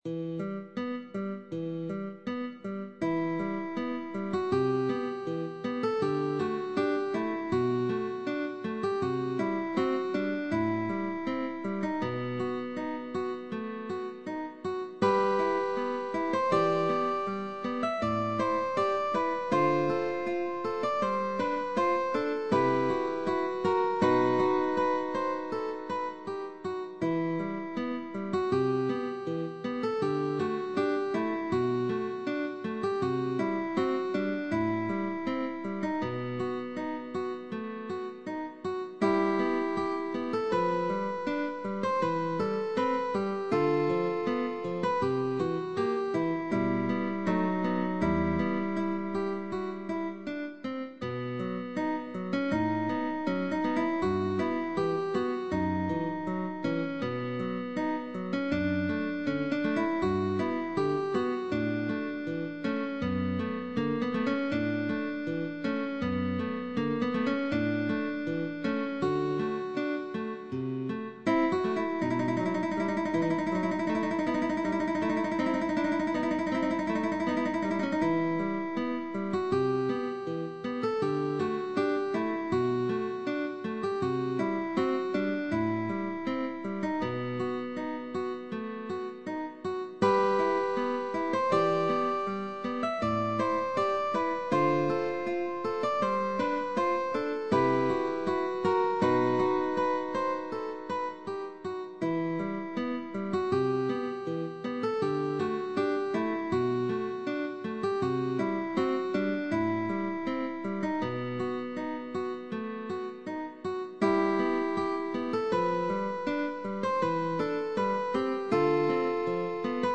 GUITAR DUO Sheetmusic.
Melodic designs, arpeggios, staccato, chords;High Positions.
F-sharp minor